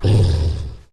Angry Chimera Growls
tb_growls
tb_lurk_1.ogg